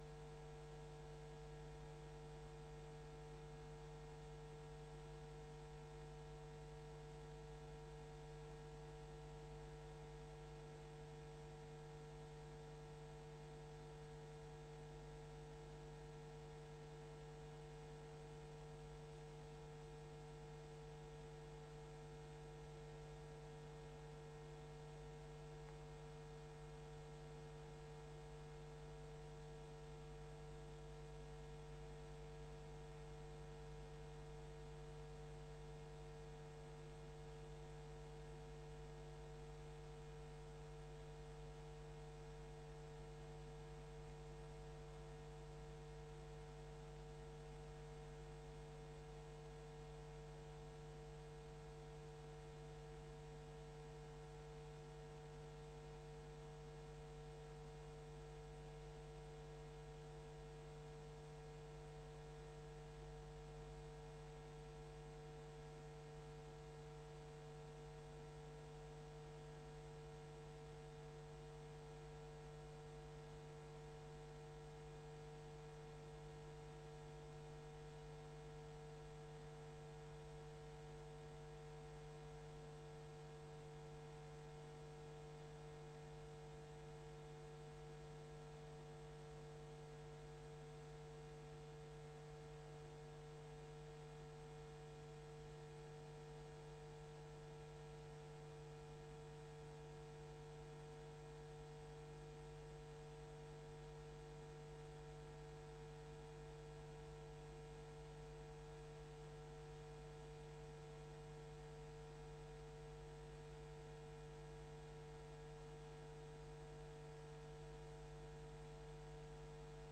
Gemeenteraad 06 november 2024 19:30:00, Gemeenteraad Hillegom
Download de volledige audio van deze vergadering